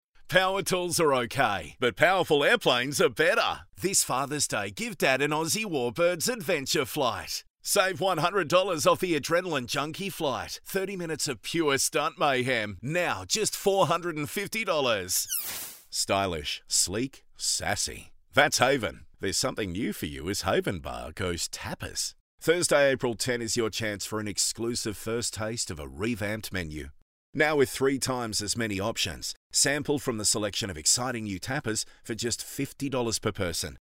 • Retail Friendly
• Neumann TLM103 / Rode NT2a / Sennheiser MKH416